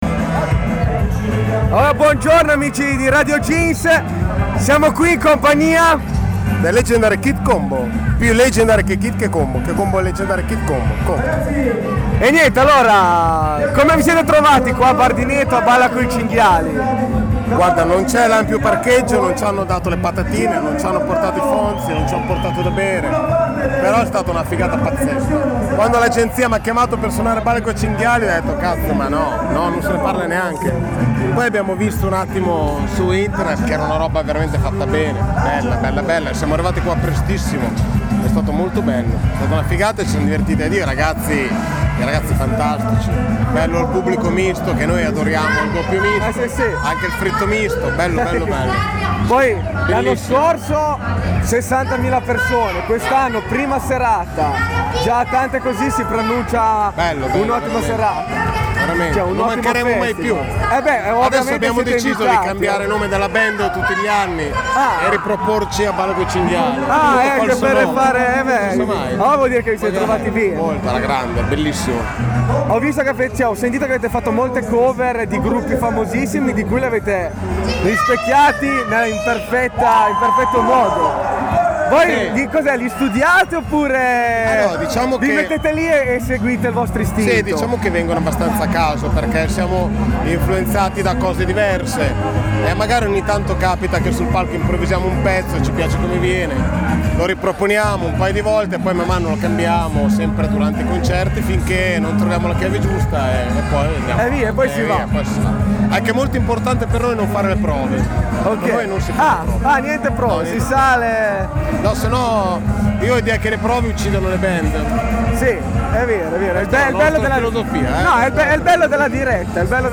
Intervista a a THE LEGENDARY KID COMBO al BALLA COI CINGHIALI 2011